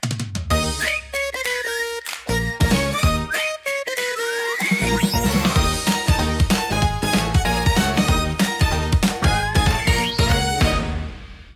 funny